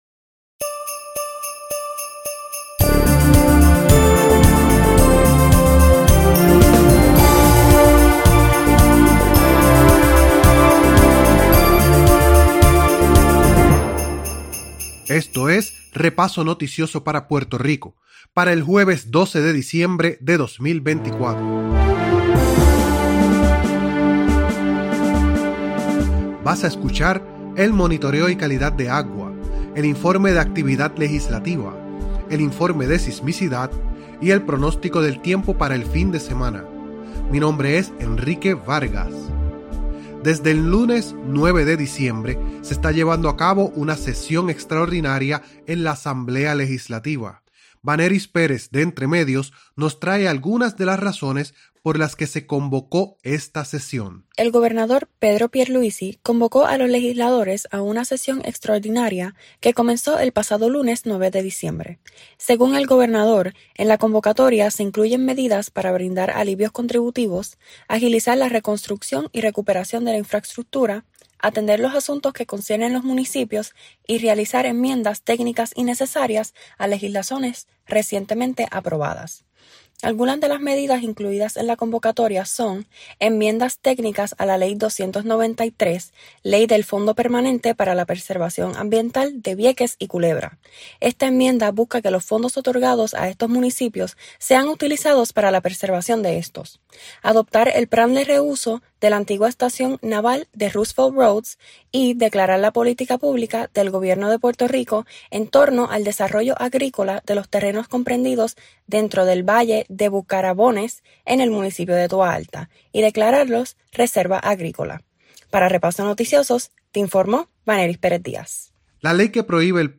Participan estudiantes del proyecto EntreMedios de la Escuela de Comunicación Ferré Rangel de la Universidad del Sagrado Corazón. El Informe de Sismicidad es preparados y presentado por estudiantes del Recinto Universitario de Mayagüez con el apoyo de la Red Sísmica de Puerto Rico .